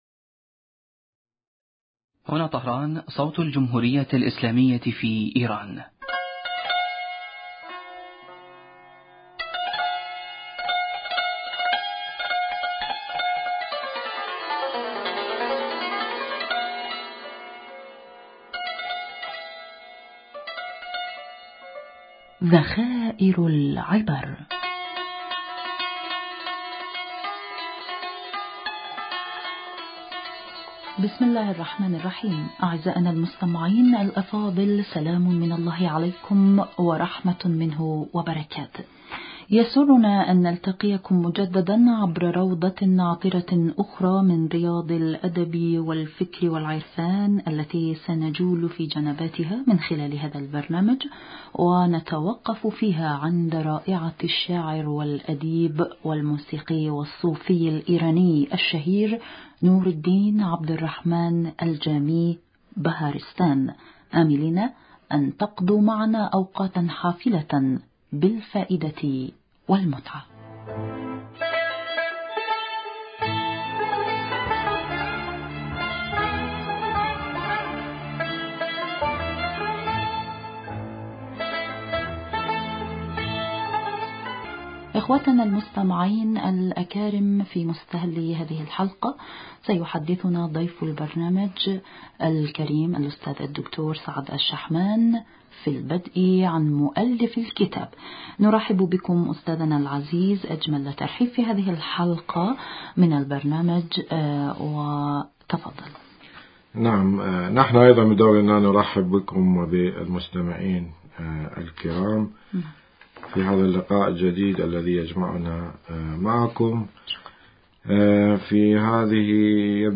المحاورة